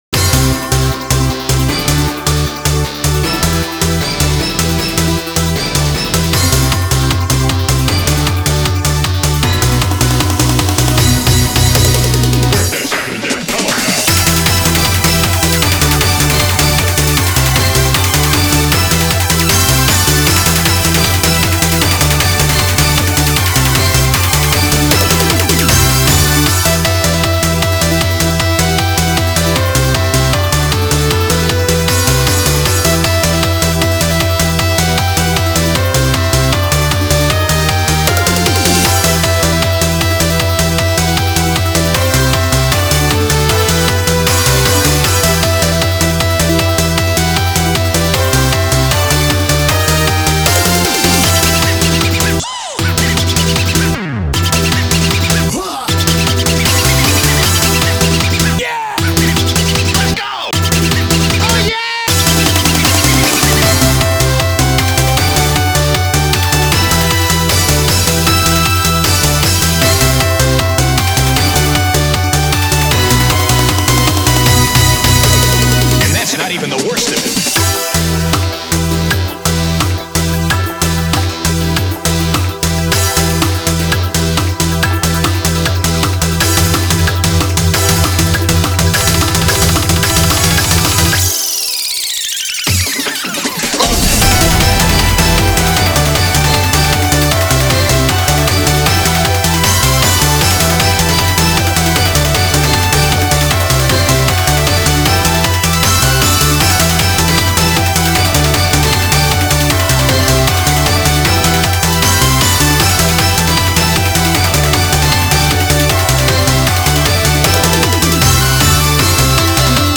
BPM77-220
Audio QualityPerfect (High Quality)
Comments[TEMPORAL EUROBEAT]